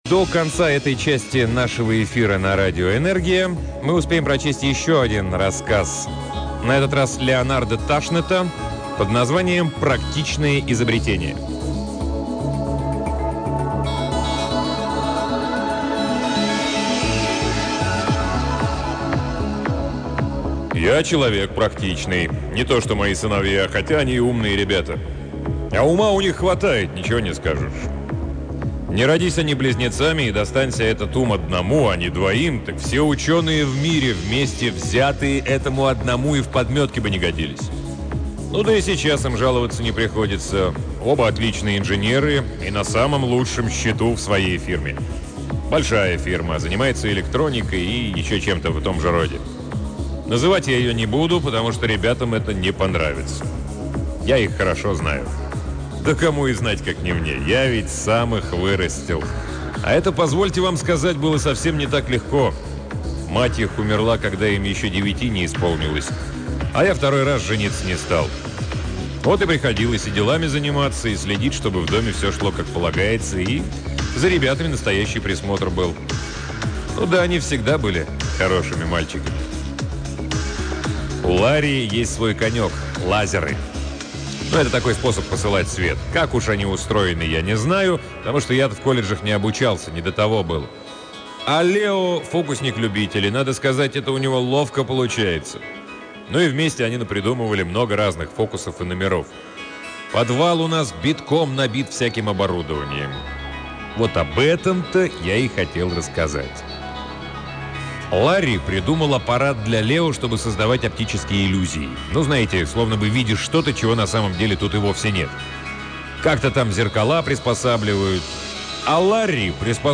Аудиокнига Леонард Ташнет — Практичное изобретение